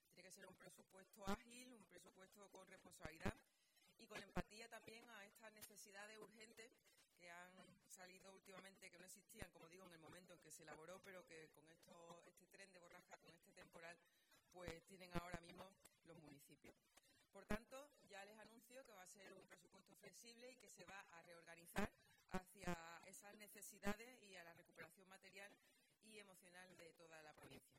Presupuesto-2026_presidenta_medios.mp3